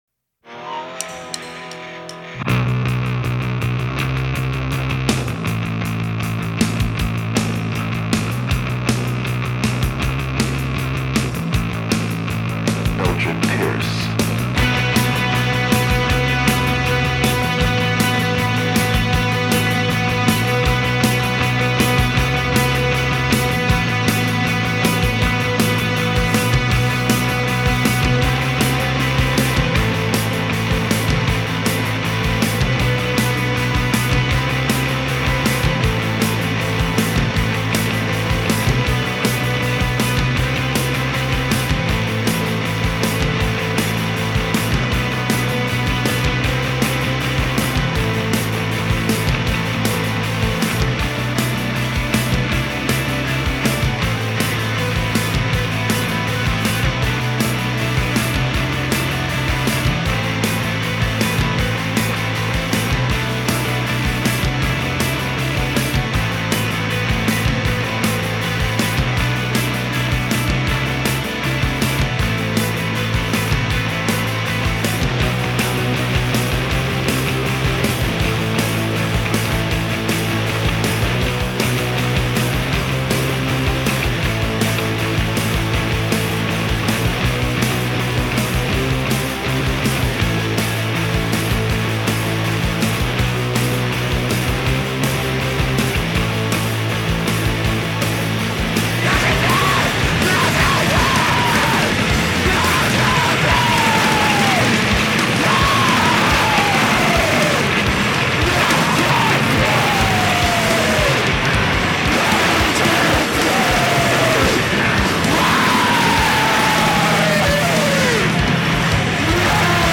Жанр: Alternative Rock